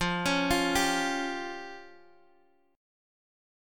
Fsus2#5 chord